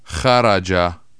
jaraya ÎóÑóÌó